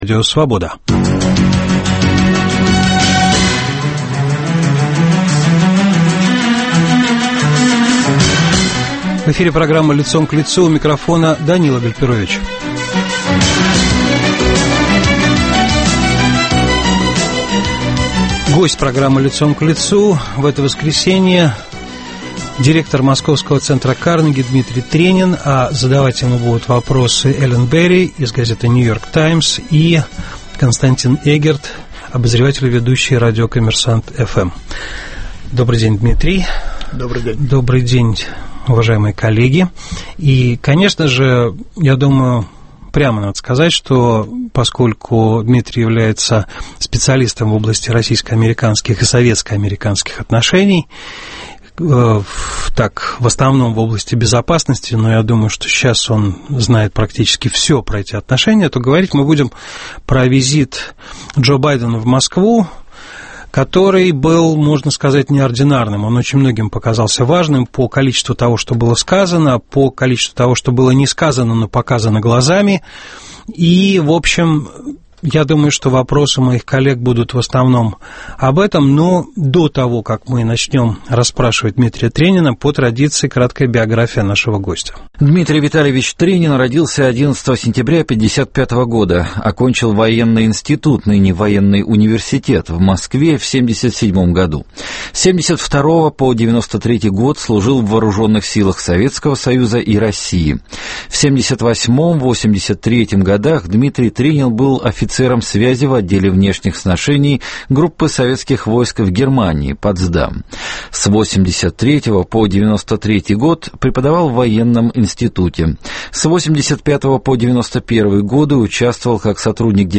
В программе - директор Московского центра Карнеги Дмитрий Тренин.